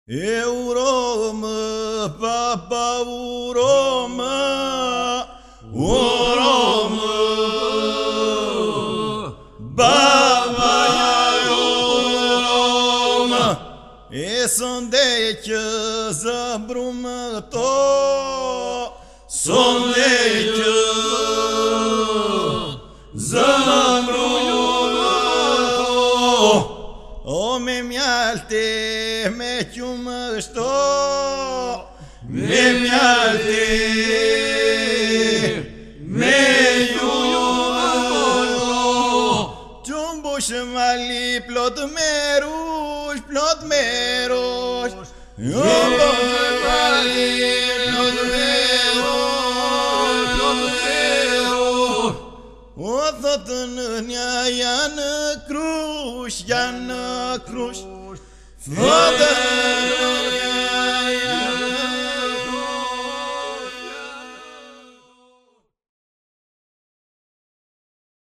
Grup më i shquar iso-polifonik i qytetit muze të Gjirokastrës, “Pasuri kulturore e njerëzimit mbrojtur nga Unesko”, i krijuar  në vitin 1976.
Është vazhdues i traditës së të kënduarit IP të “Grupit të Pleqve” dhe veçanërisht i këngëve IP qytetare tradicionale.
Database of Albanian Folk Iso-Polyphony